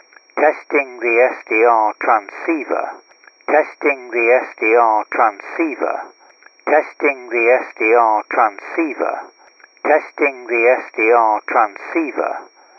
CQ_Test.wav